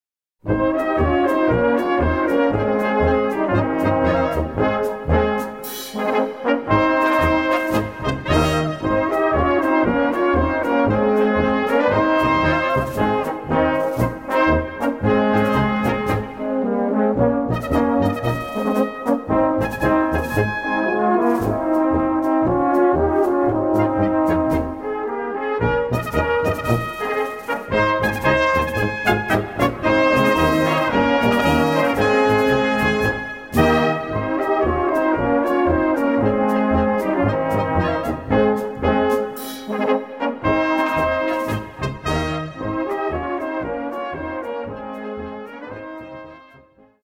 Gattung: Böhmische Polka
Besetzung: Blasorchester
Eine herrliche böhmische Polka.